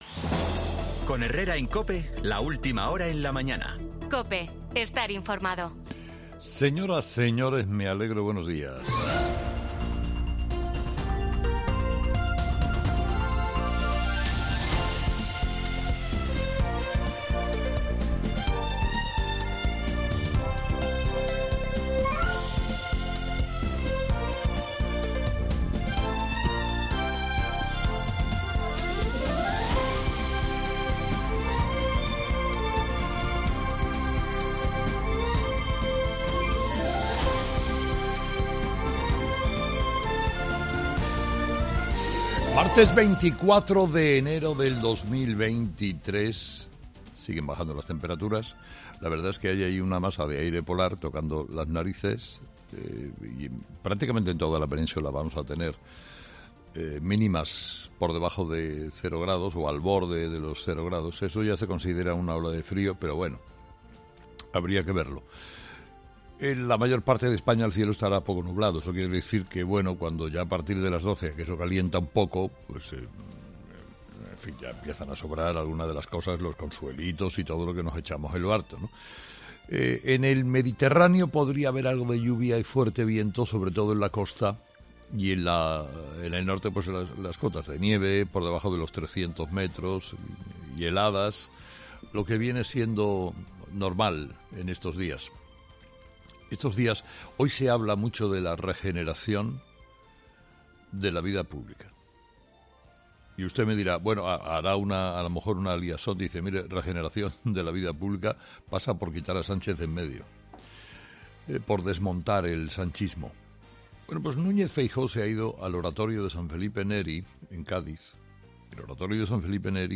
Carlos Herrera, director y presentador de 'Herrera en COPE', ha comenzado el programa de este lunes analizando las principales claves de la jornada, que pasan, entre otros asuntos, por el pacto que ha propuesto Alberto Núñez Feijóo para que gobierne la lista más votada y el resto de su propuesta de "calidad institucional" en el Oratorio de San Felipe Neri, en Cádiz.